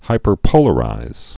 (hīpər-pōlə-rīz)